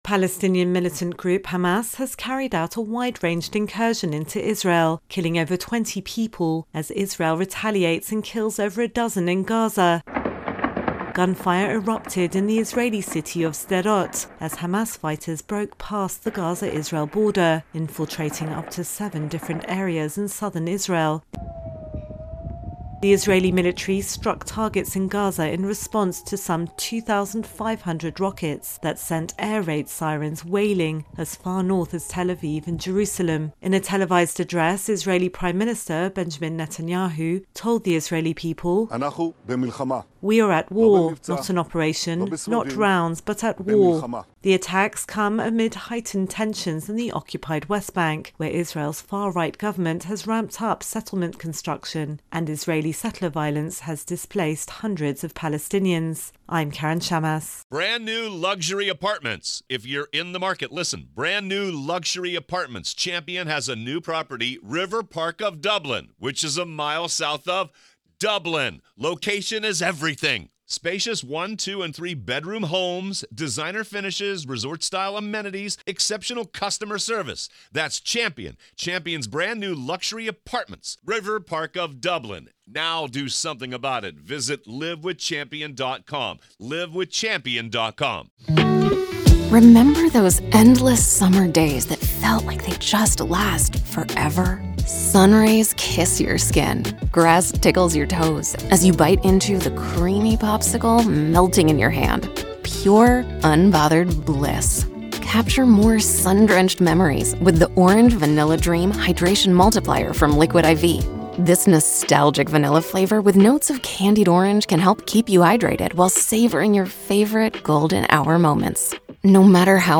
reports on Israel Palestinians Attack.